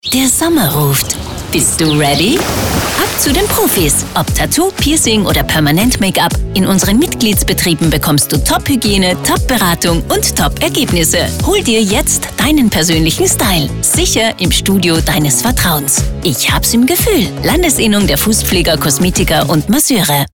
FKM: Radiospots auf KRONEHIT on air
spot-wko-piercen-taetowieren-permanent-makeup.mp3